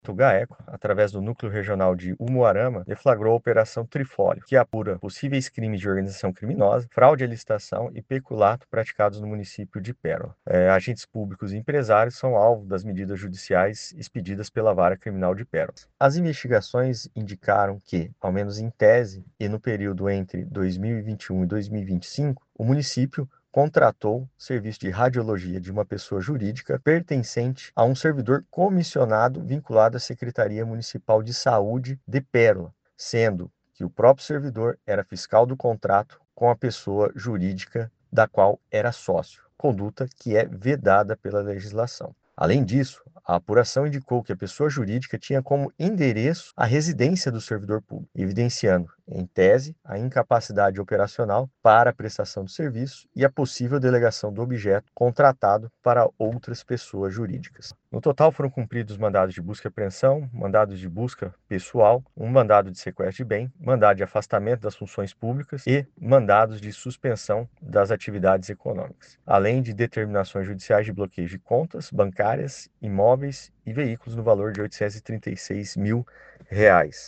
Os alvos das ordens judiciais, expedidas pela Vara Criminal de Pérola, são agentes públicos e empresários, como explica o promotor de Justiça Guilherme Franchi da Silva Santos.